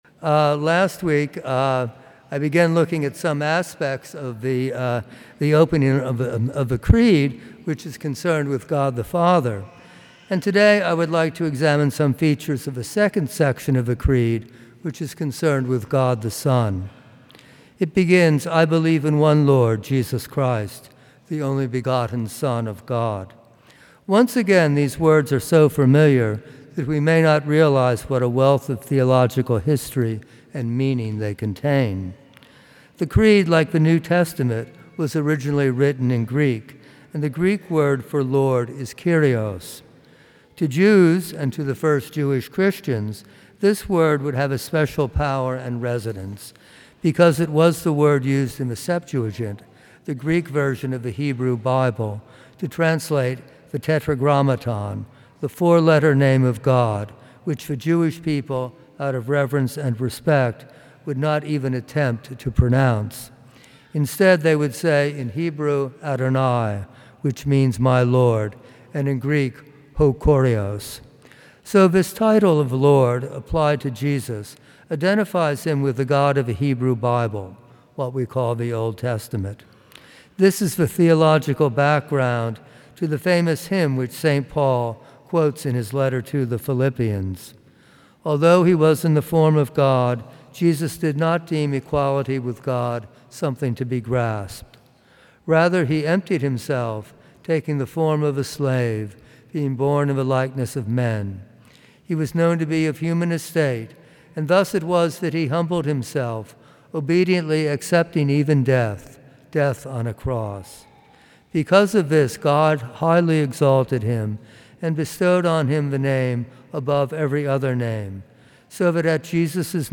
A homily from the series "Homilies."